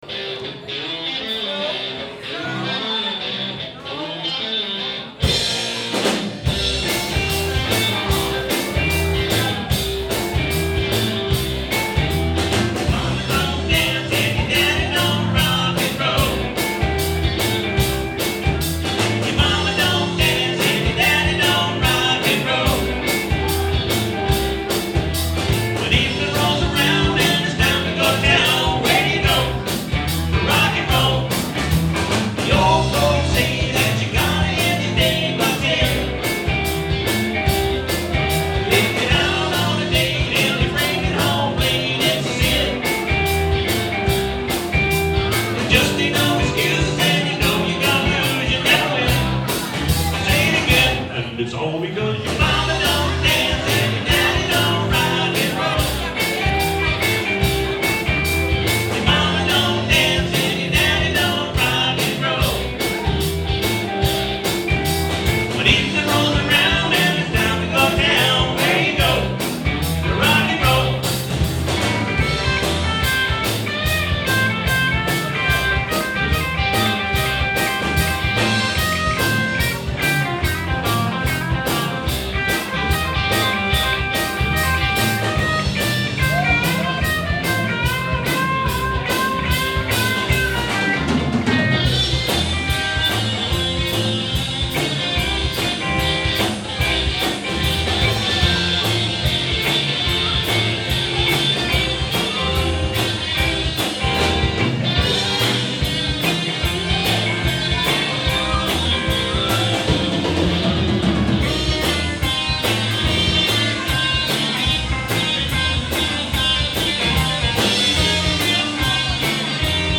Guitar, Keyboards, Vocals
Bass, Vocals
Guitar, Vocals, Keys, Harmonica
Drums, Vocals